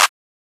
Common Clap 2 (CLAP).wav